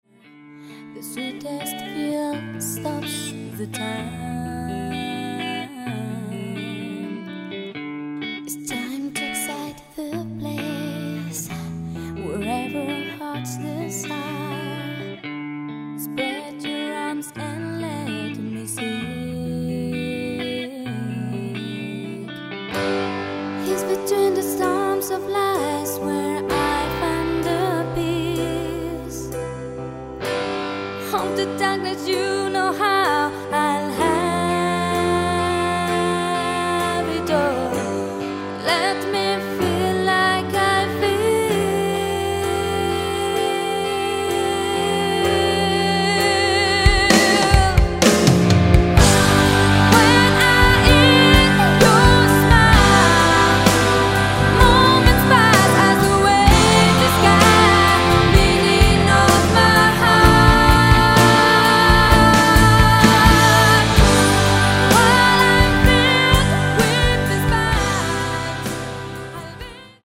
Bass & Backing Vocals
Acoustic and Electric Guitars & Backing Vocals
Drums & Percussion